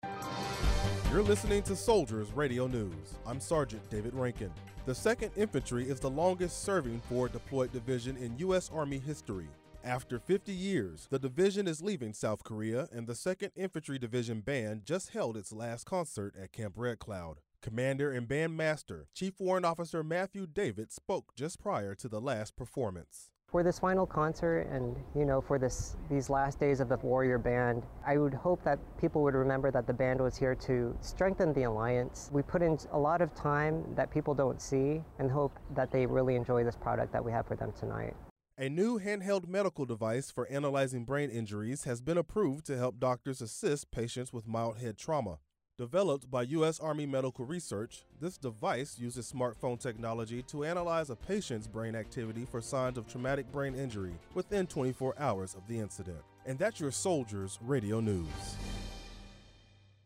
dmaSoldiers Radio NewsSRNArmy